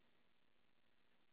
kyo 0141 (Monaural AU Sound Data)